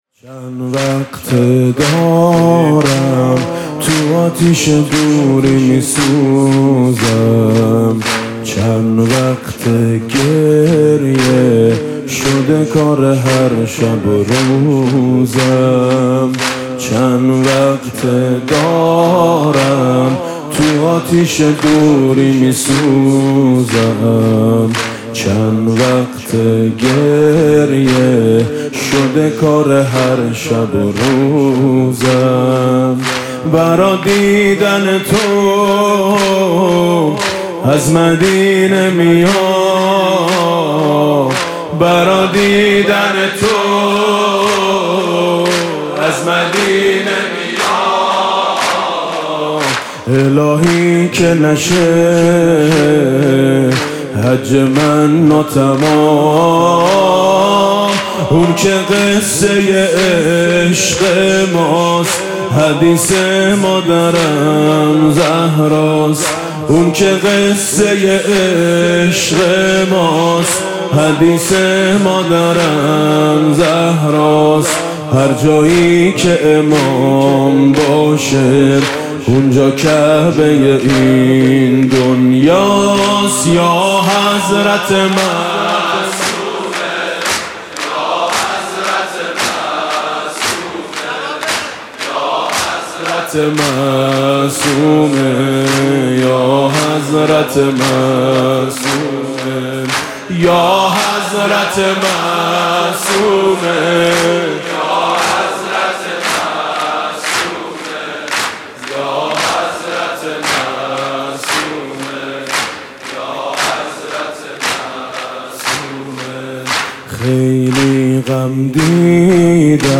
حاج میثم مطیعی
19 اذر 98 - زمینه - اهل قم بودن، خیال تو راحت باشه